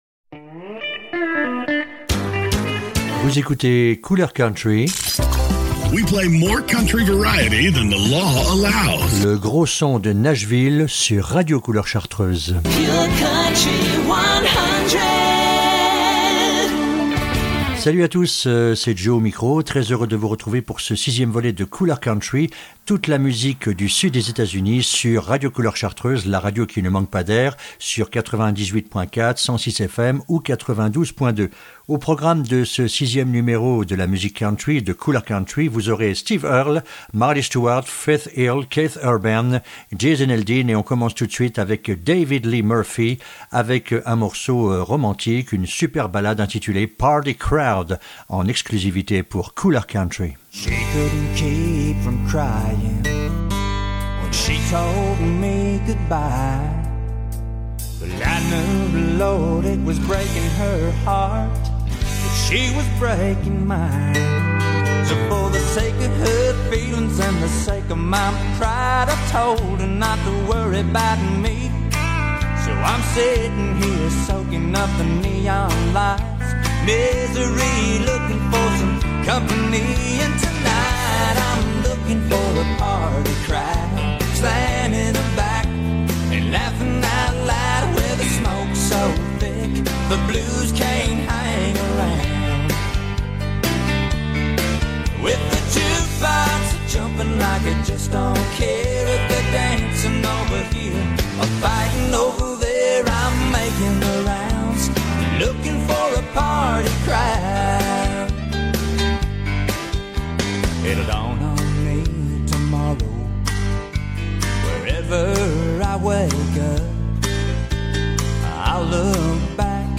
Ceux du sud des Etats unis : la musique Country ! L’émission de Mai 2024 à suivre avec l’agenda à découvrir !